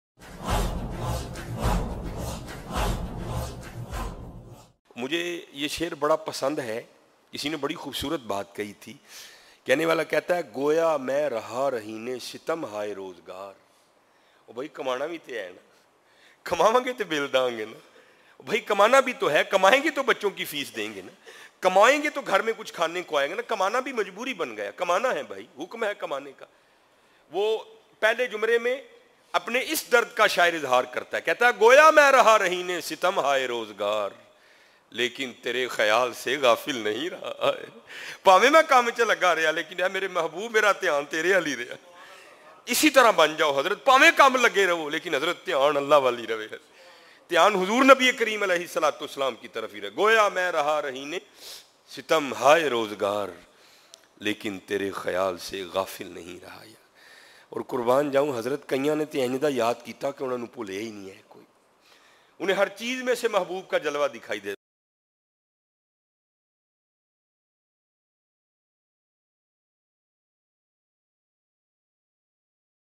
Bayan MP3